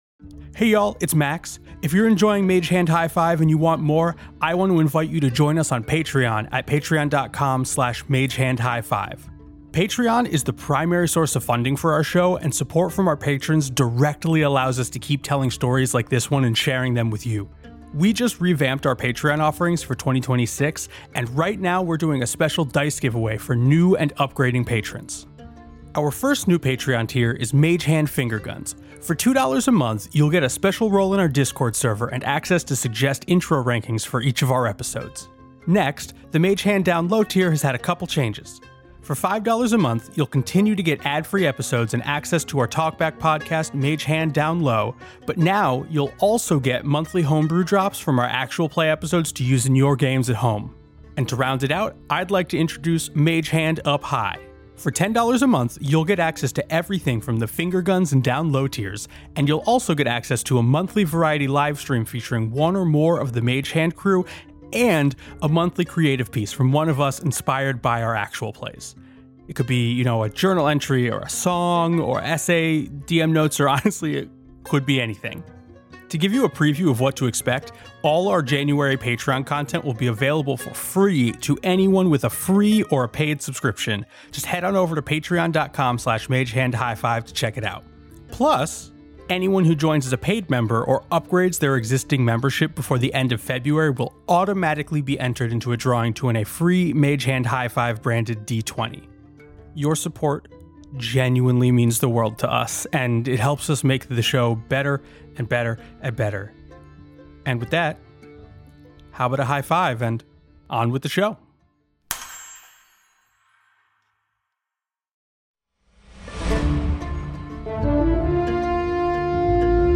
actual play podcast